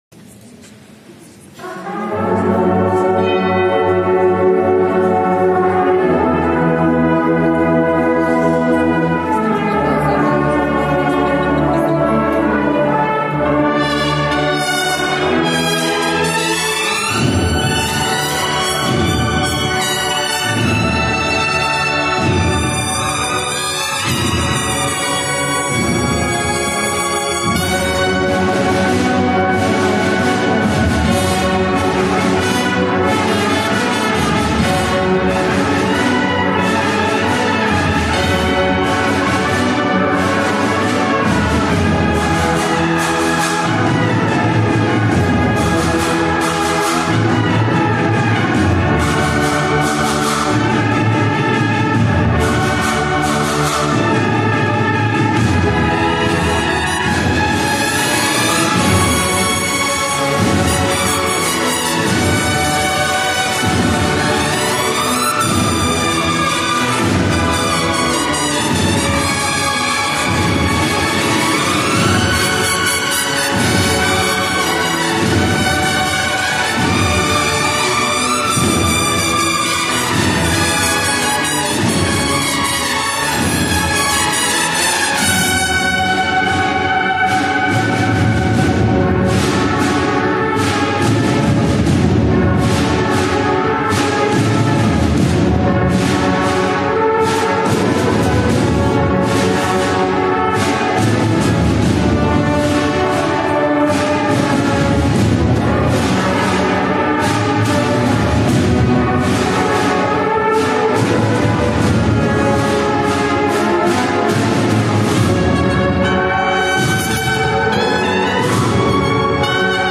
marcha